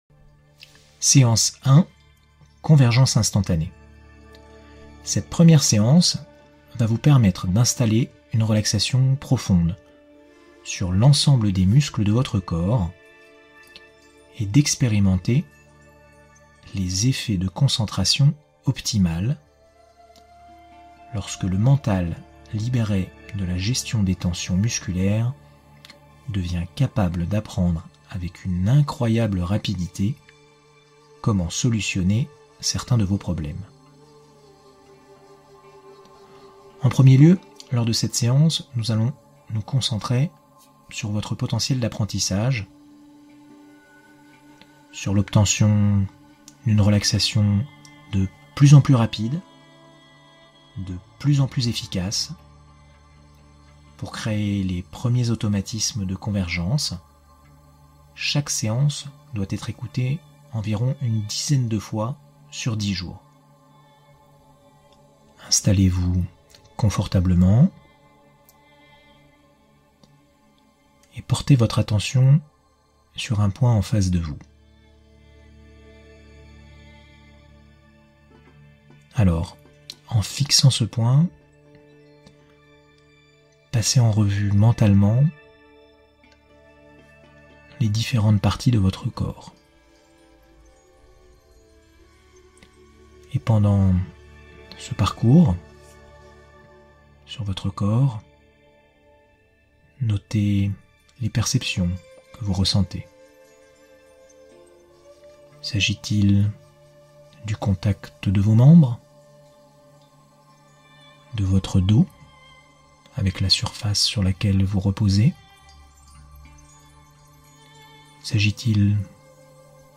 Soulager la douleur : série de 6 séances d’hypnose